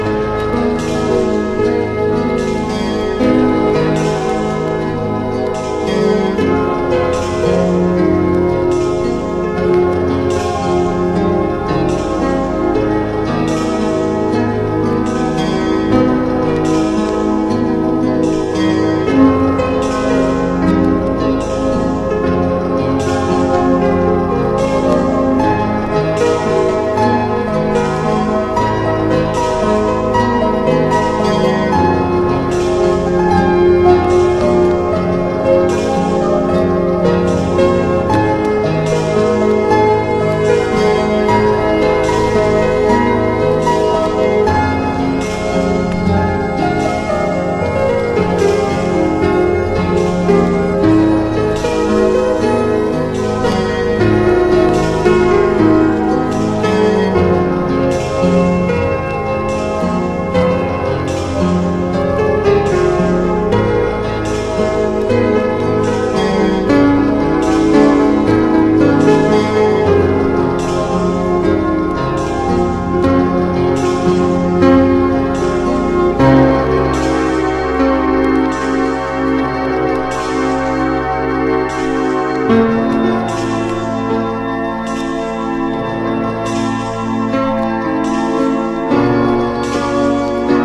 キュートなエロ・モンド・シンセサイザー・コンピの決定盤！
70年代に制作されたチープでキッチュな、愛らしい電子音にとことん浸れます！